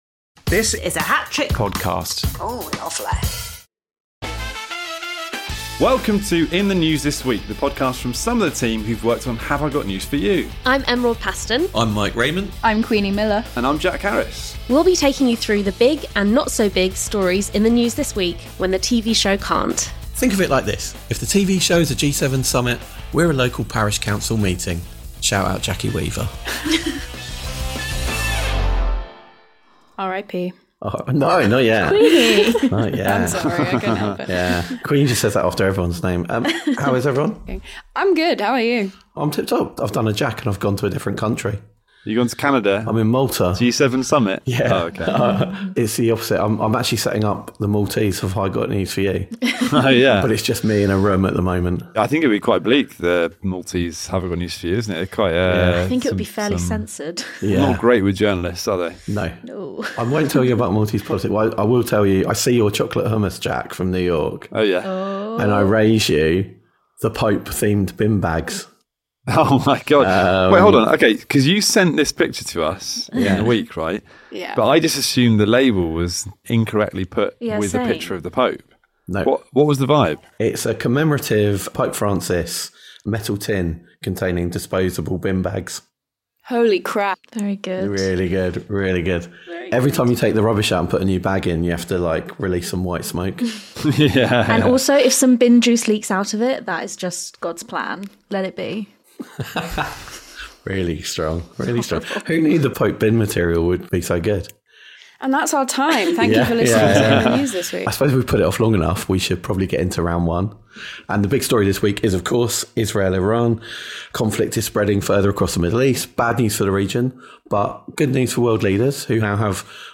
The team chat about how pizzas at the pentagon led to correct predictions of rising tensions between Israel and Iran, schoolground antics at the G7 (otherwise known as the G6+1), Starmer’s papers pickup and Trump’s pathetic military parade…